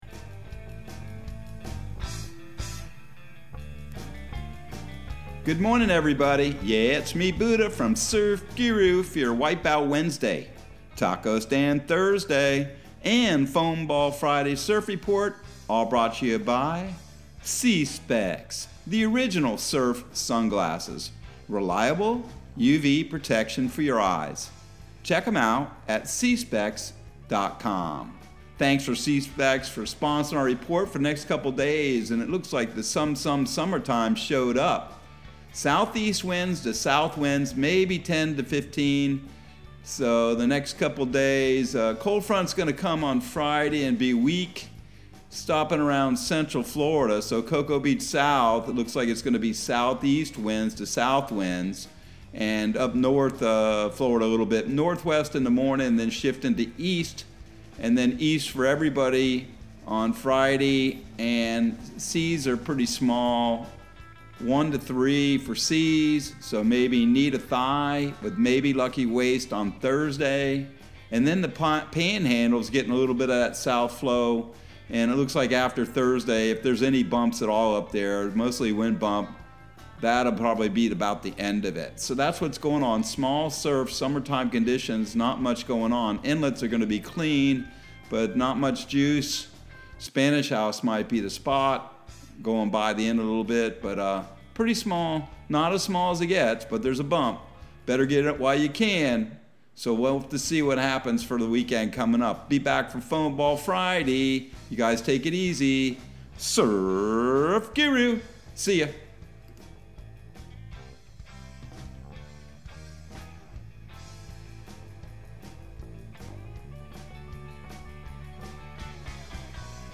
Surf Guru Surf Report and Forecast 04/13/2022 Audio surf report and surf forecast on April 13 for Central Florida and the Southeast.